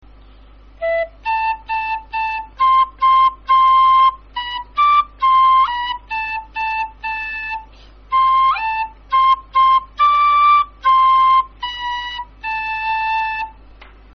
Награш: сопілка (mp3)